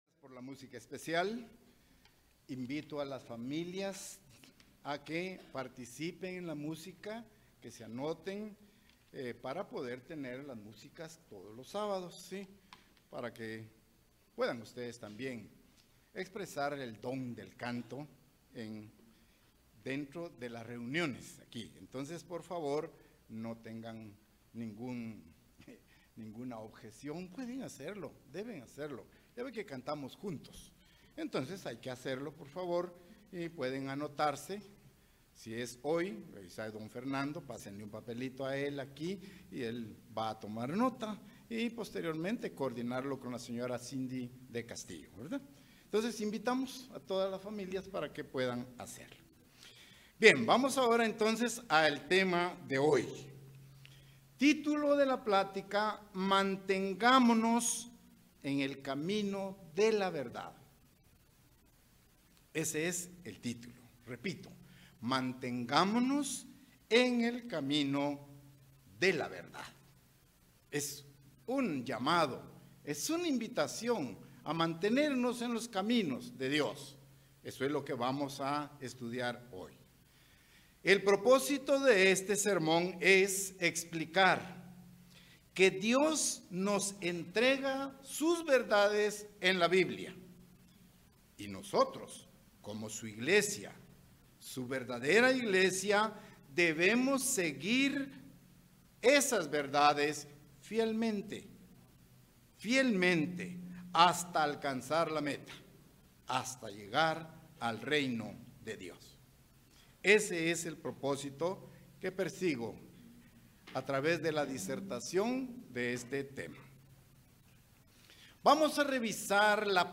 Given in Ciudad de Guatemala